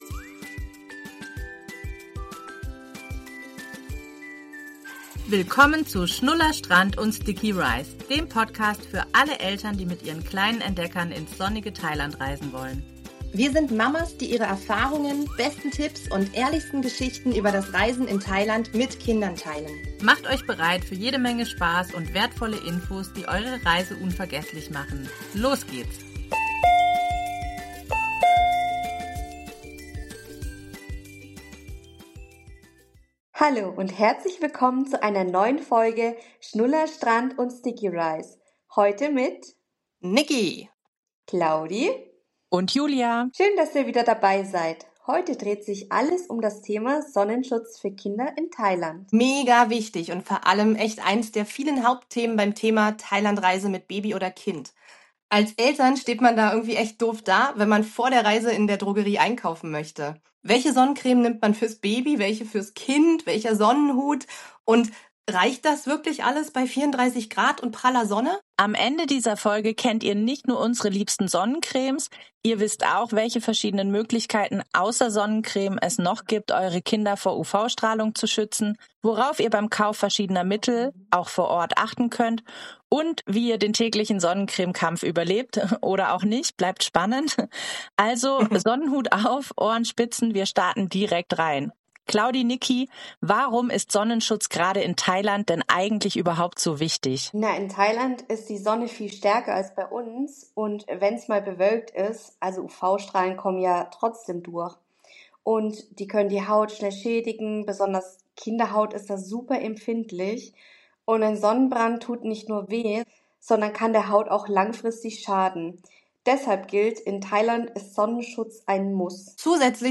drei Mamas mit Fernweh, Sonnencreme im Gepäck und ganz viel Herz für Thailand.